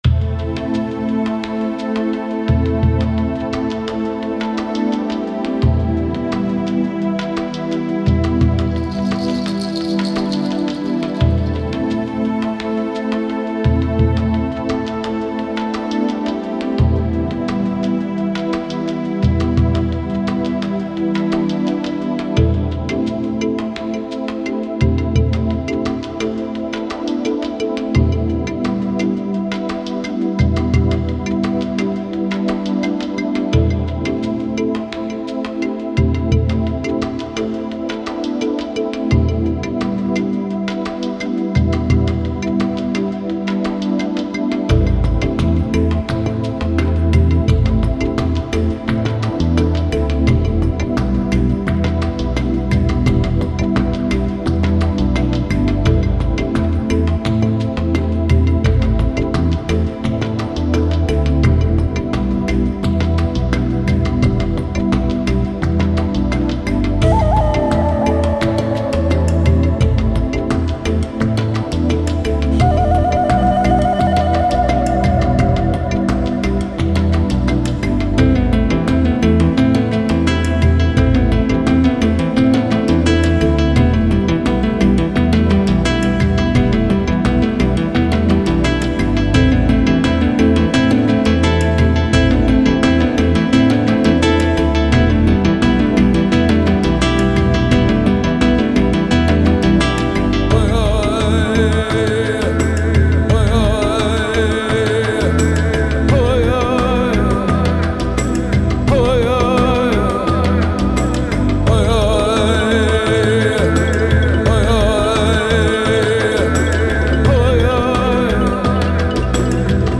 Genre: Native American.